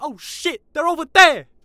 Barklines Combat VA